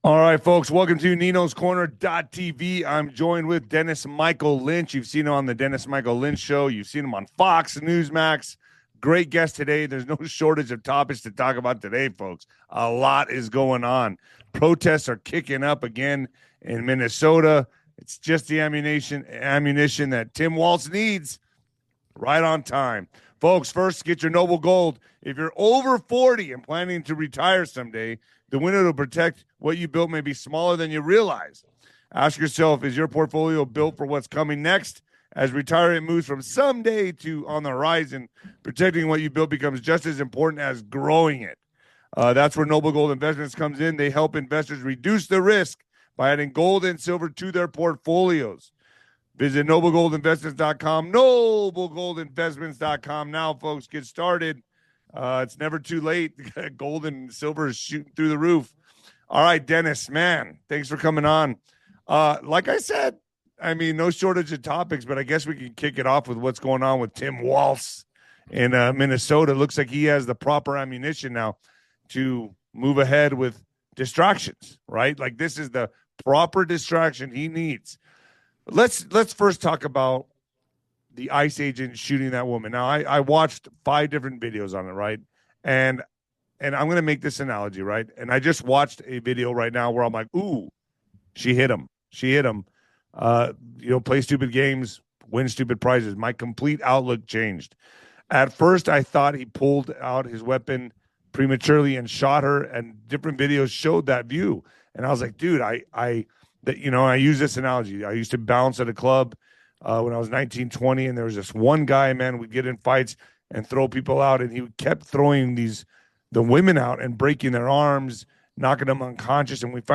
They explore a controversial ICE incident, debate political strategies, and express concerns about AI's impact on jobs. The conversation touches on global politics and the urgency of resolving key issues to avoid drastic measures. It's a lively discussion with plenty of food for thought!